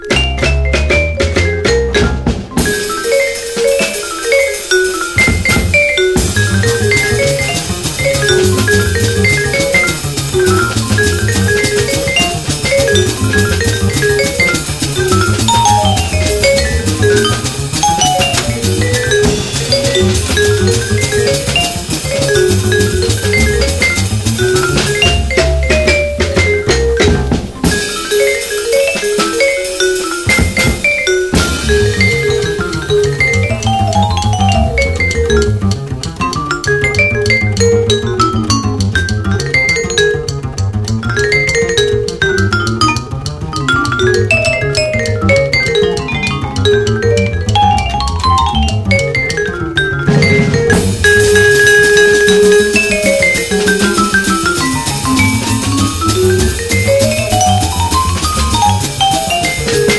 WEST COAST JAZZ